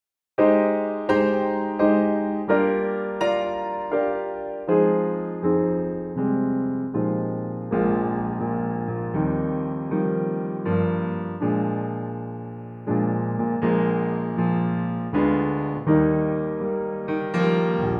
key - Eb - range - Bb to Bb